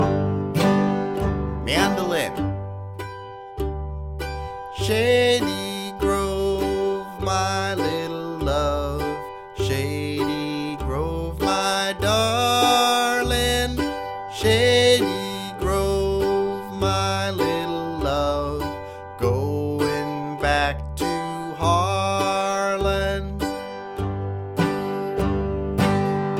super-slow (key of G)
Rhythm: All Instruments